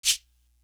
Bring You Light Shaker.wav